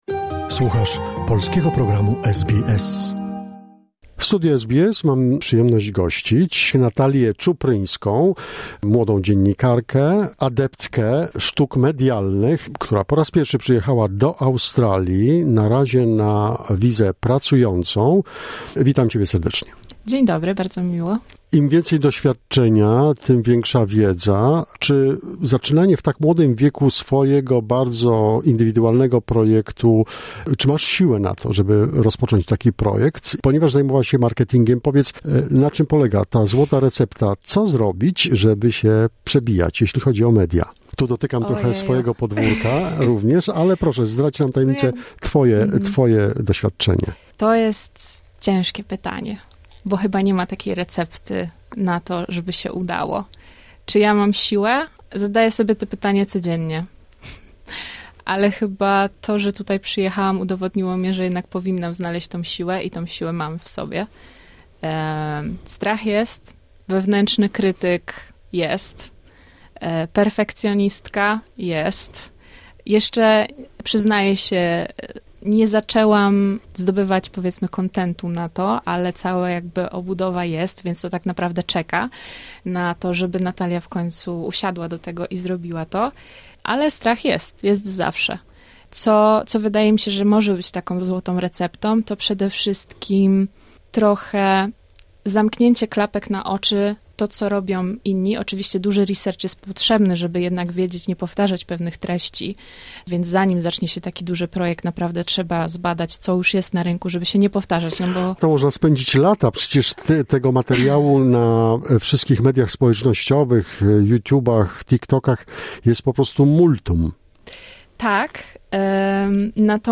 w SBS Melbourne